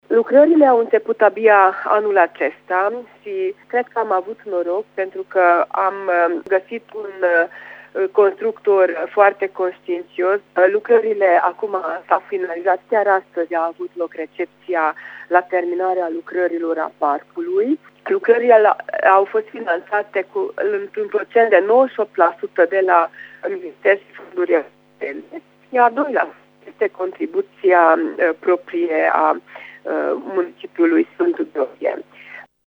Din acest motiv, mare parte din construcție a fost efectuată anul acesta, iar recepția a fost făcută abia astăzi, spune viceprimarul municipiului Sfântu Gheorghe, Sztakics Éva: